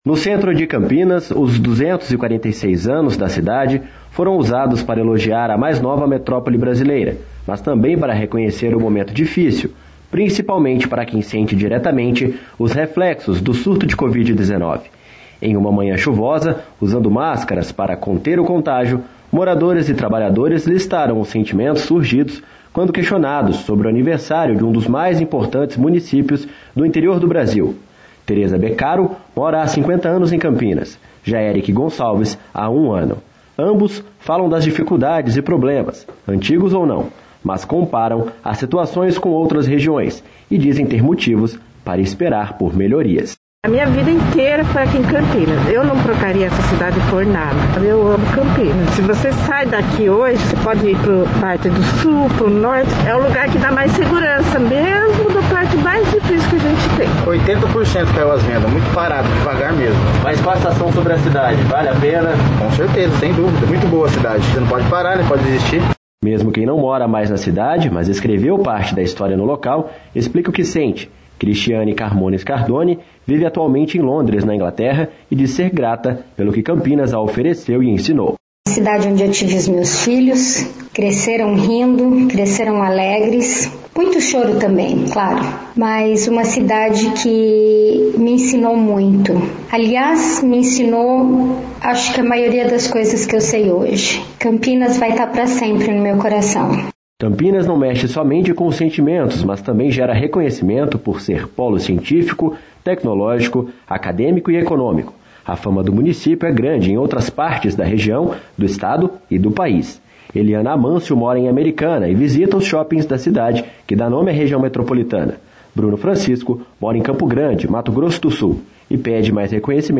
Em uma manhã chuvosa, usando máscaras para conter o contágio, moradores e trabalhadores listaram os sentimentos surgidos quando questionados sobre o aniversário de um dos mais importantes municípios do interior do Brasil.